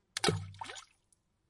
外部 " 泼水节 3
标签： 石头 飞溅 防水 防摔 噗的一声 石头
声道立体声